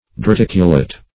dearticulate - definition of dearticulate - synonyms, pronunciation, spelling from Free Dictionary
Dearticulate \De`ar*tic"u*late\